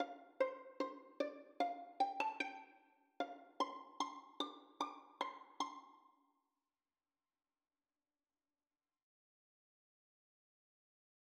베토벤의 현악 사중주 F장조, 작품 135의 피날레에는 오음음계가 사용되었다.